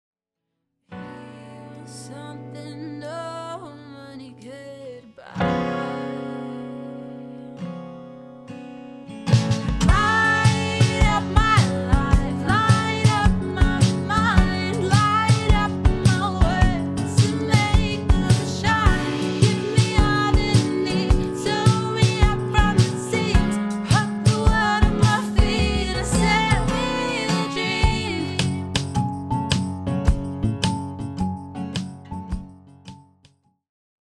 Country/Folk/Acoustic